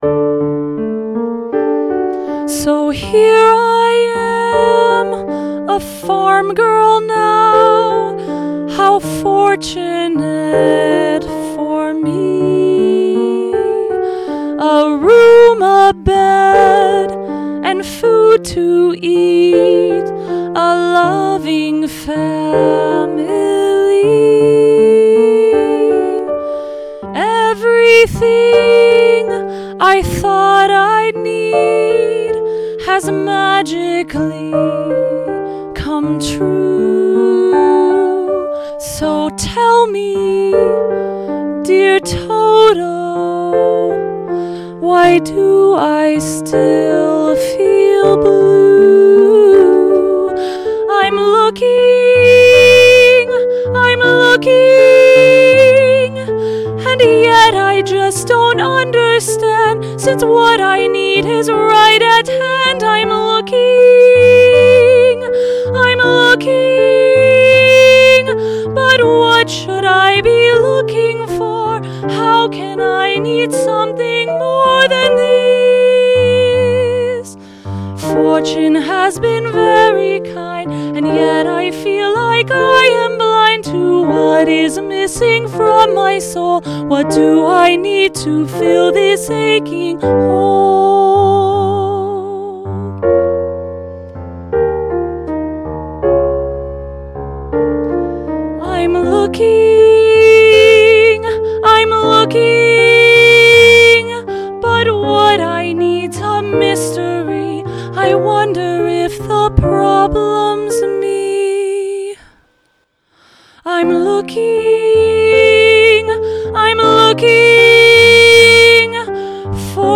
The primary vibe is the golden age of Broadway, but there’s some gospel, there’s jazz, there’s funk; there’s even a bit of boy-band/BTS snuck in here and there. Here are some rough demos of a few of the songs: